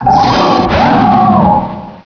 pokeemerald / sound / direct_sound_samples / cries / thundurus_therian.aif